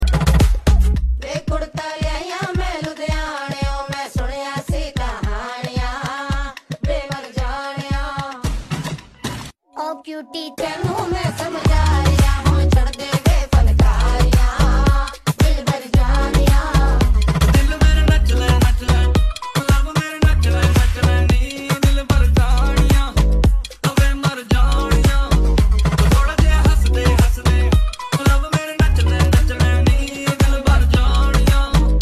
energetic tune